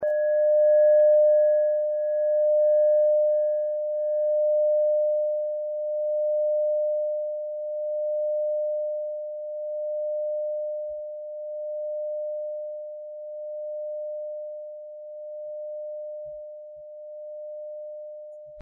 Klangschale Nepal Nr.10
Klangschale-Gewicht: 950g
Klangschale-Durchmesser: 14,1cm
(Ermittelt mit dem Filzklöppel)
klangschale-nepal-10.mp3